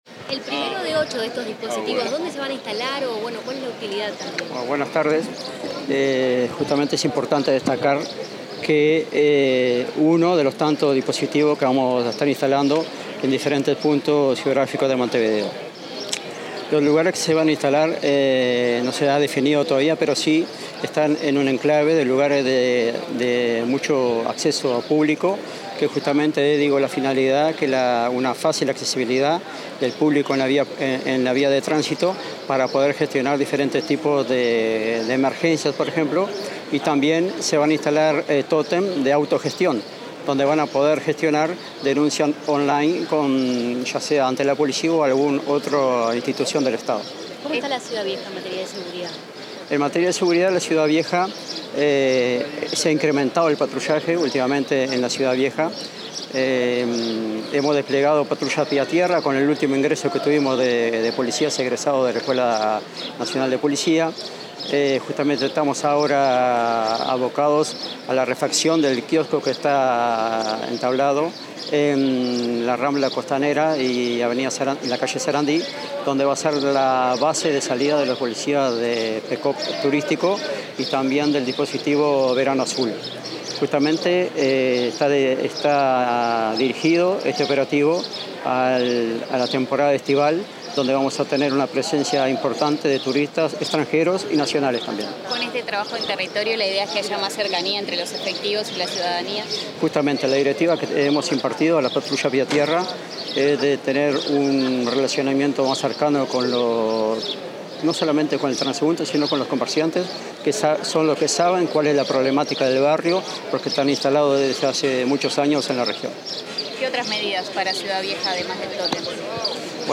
Declaraciones del jefe de Policía de Montevideo, Pablo Lotito
Declaraciones del jefe de Policía de Montevideo, Pablo Lotito 27/11/2025 Compartir Facebook X Copiar enlace WhatsApp LinkedIn Tras participar de la presentación del primer tótem de punto seguro 911 en la Ciudad Vieja, el jefe de Policía de Montevideo, Pablo Lotito, dialogó con la prensa.